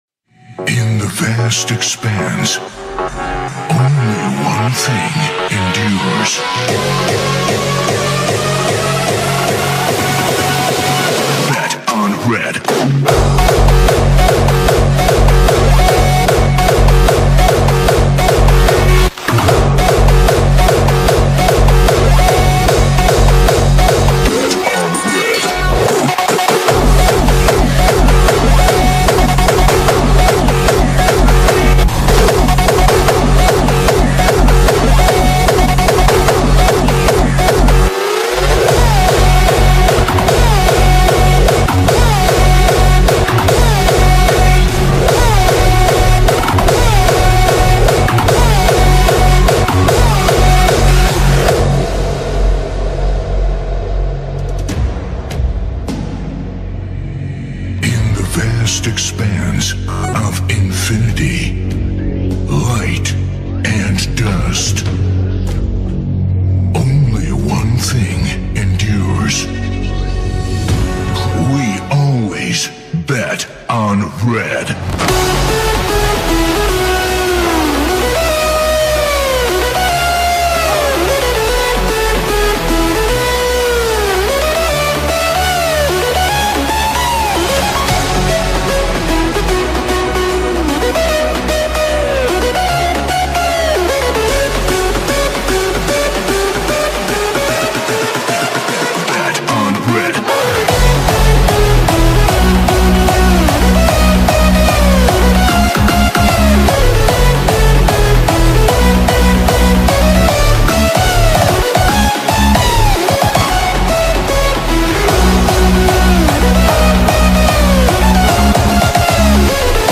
BPM150
HARDSTYLE